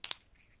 chipsHandle1.ogg